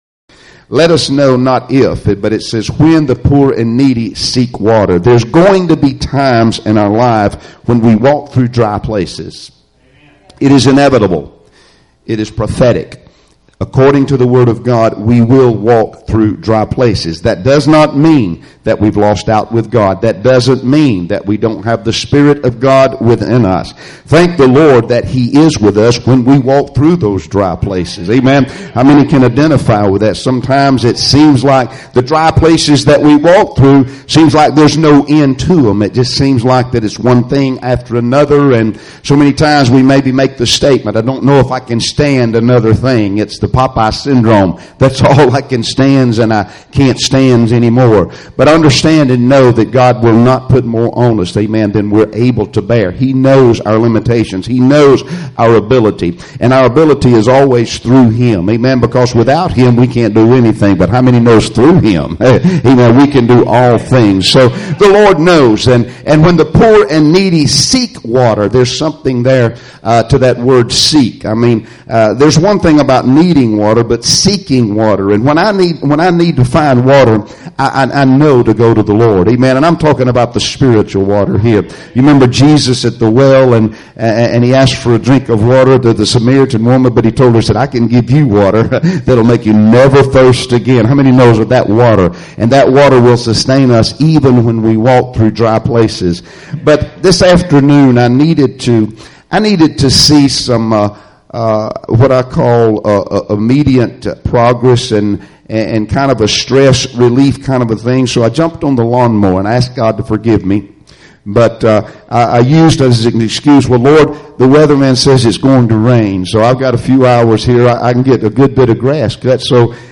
Passage: Isaiah 41:17-18 Service Type: Sunday Evening Services Topics